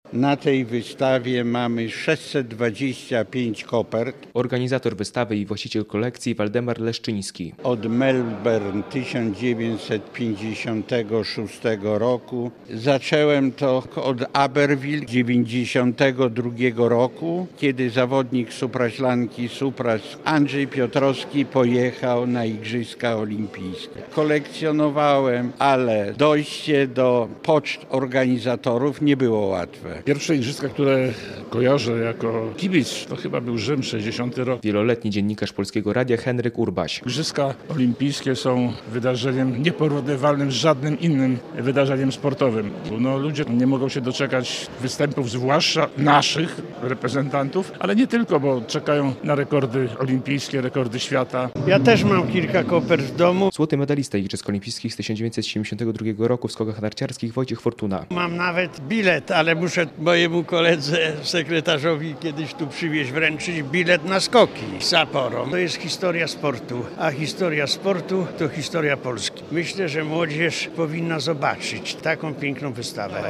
Wystawa kopert olimpijskich - relacja